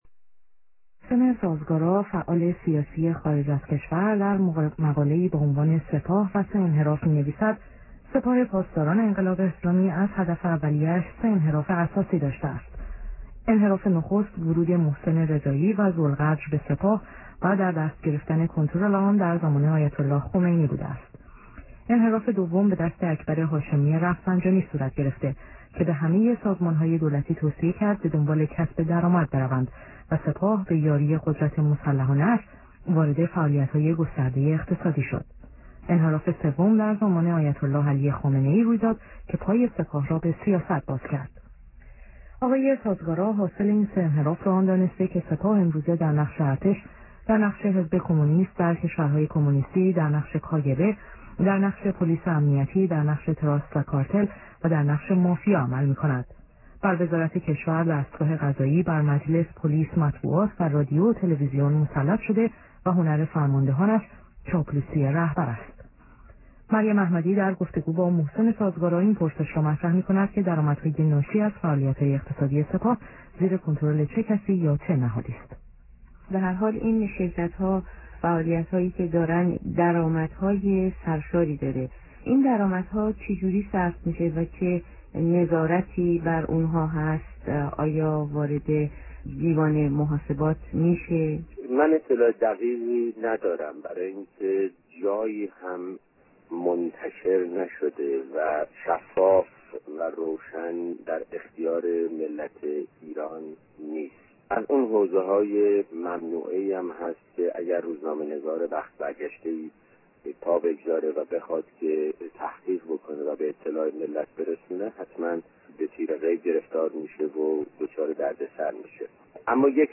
دوشنبه ۲ مردادماه ۱۳۸۵ - ۳:۳۰ بعدازظهر | مصاحبه ها